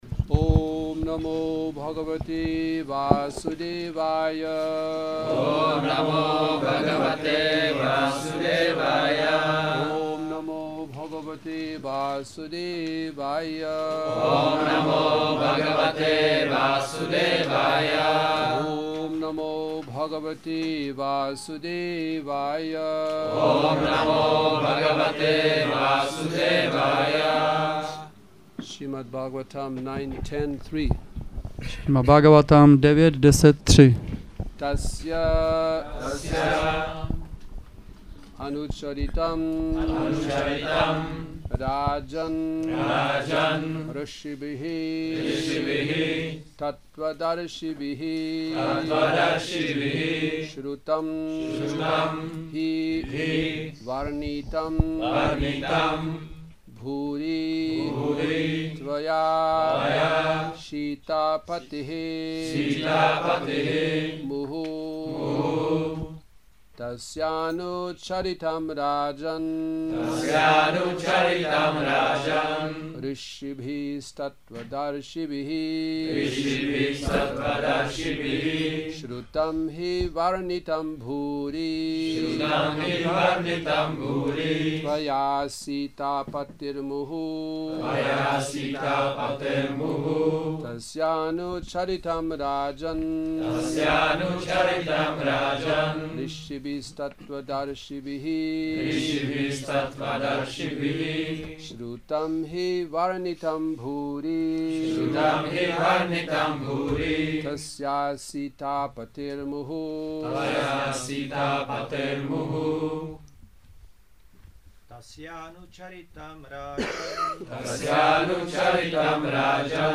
Šrí Šrí Nitái Navadvípačandra mandir
Přednáška